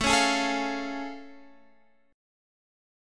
A+M7 chord